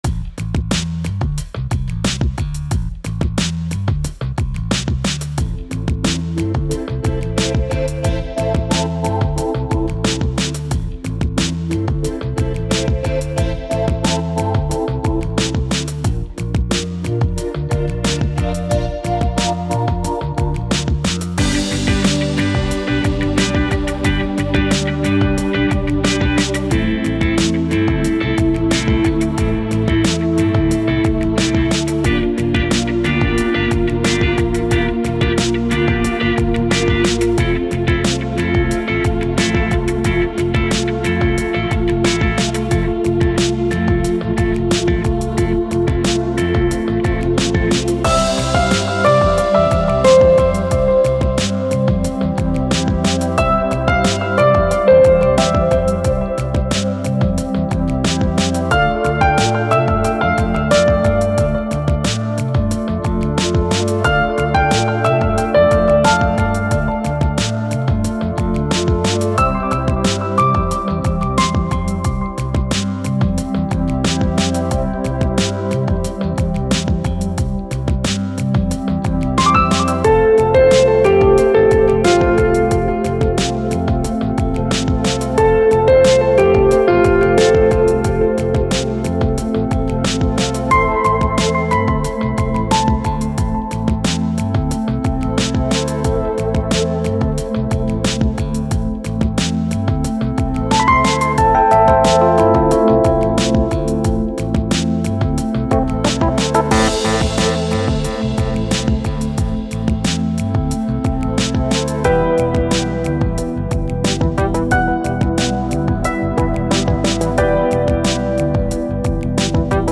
με chillout διάθεση